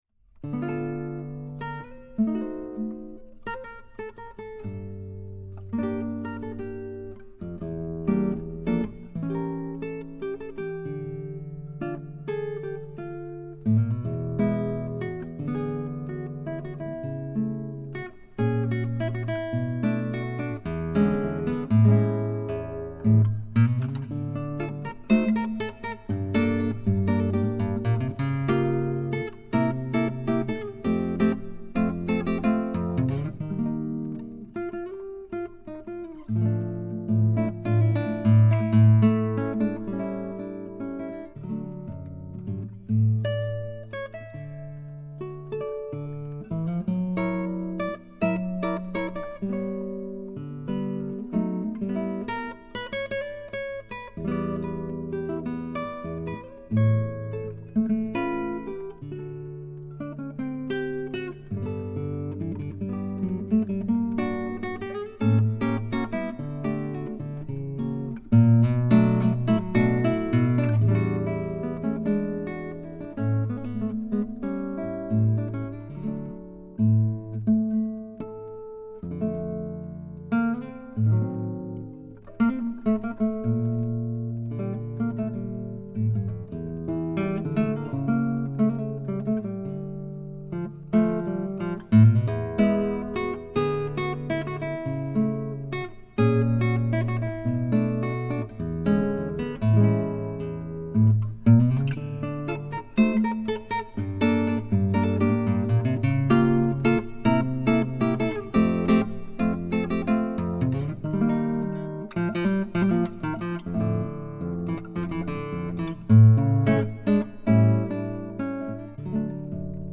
(Tangos y una Guitarra)
Guitarra y Arreglos